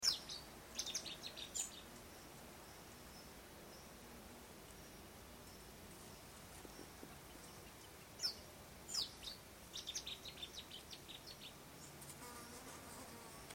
bouscarle_chant.mp3